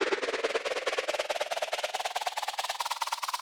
Transition (17).wav